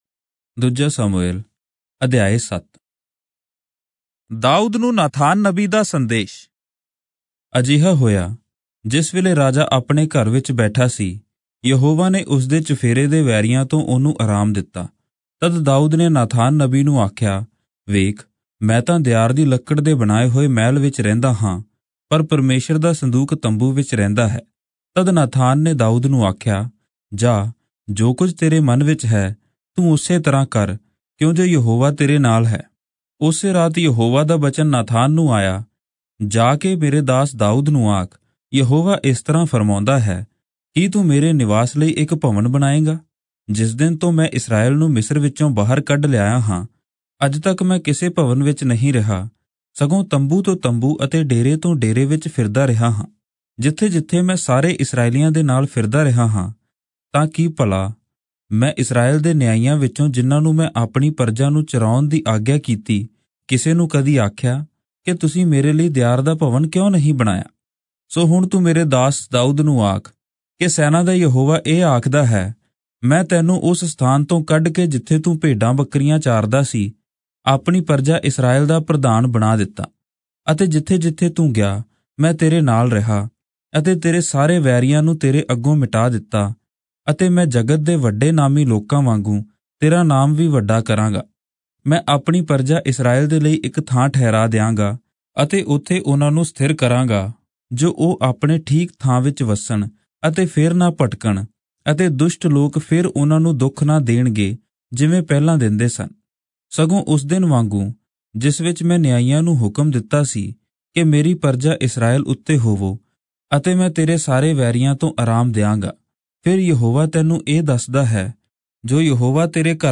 Punjabi Audio Bible - 2-Samuel 17 in Irvpa bible version